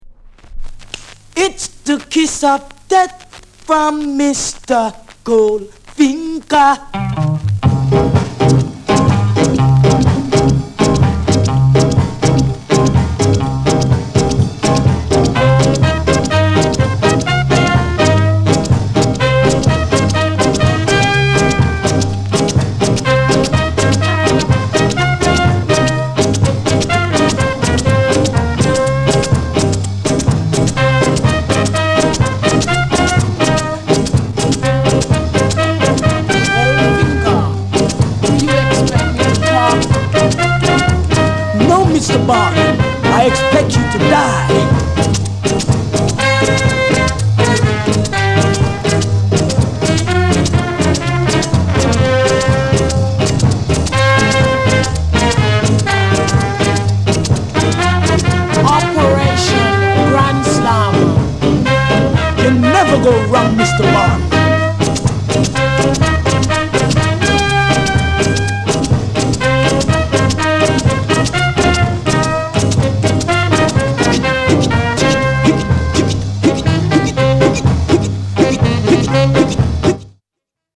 KILLER SKA INST